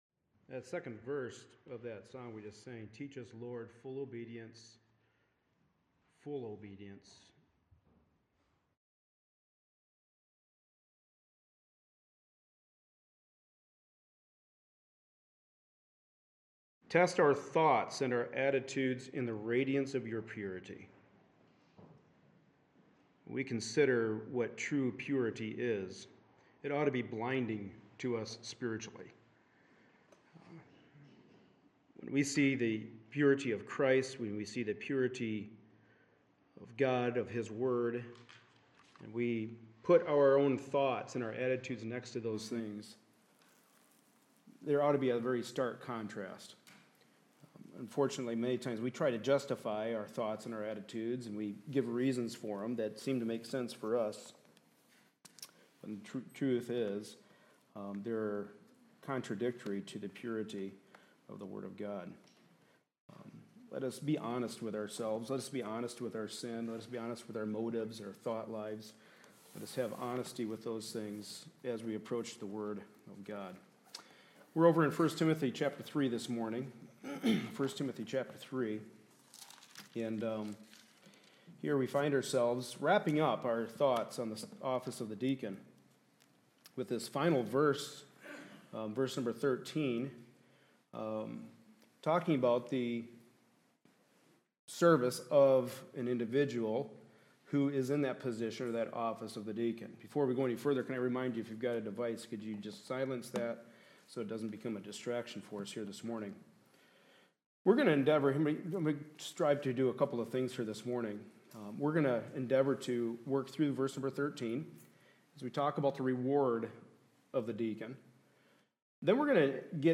1 Timothy 3:8-16 Service Type: Sunday Morning Service A study in the Pastoral Epistles.